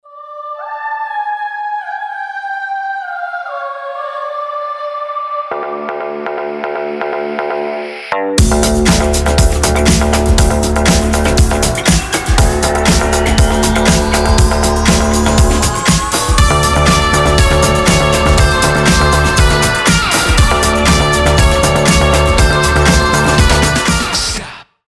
рок
alternative